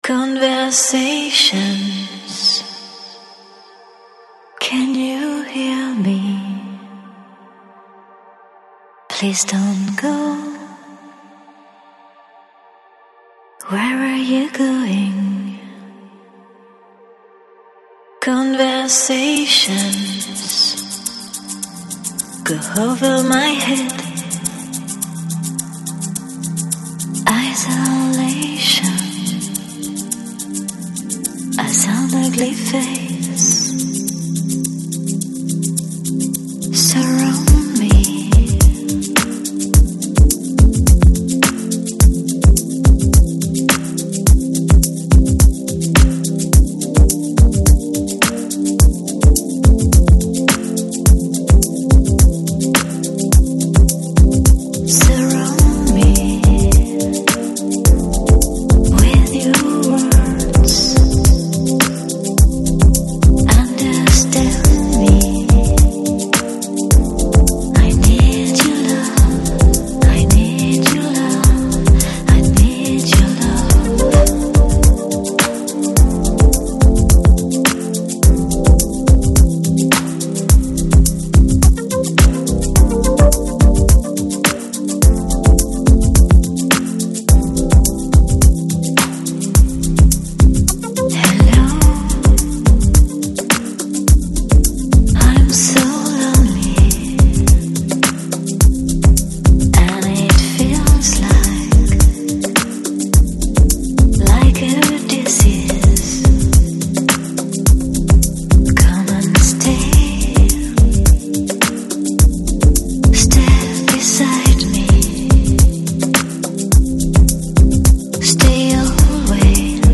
Electronic, Lounge, Downtempo